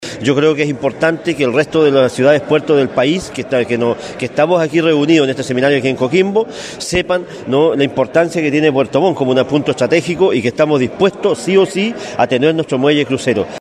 Según indicó el alcalde Gervoy Paredes, es importante que el resto de las ciudades puerto de Chile sepan de este objetivo, por la importancia estratégica de Puerto Montt